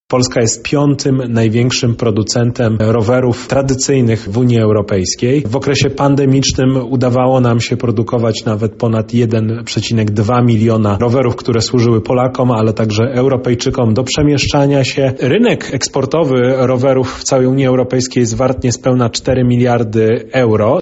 -mówi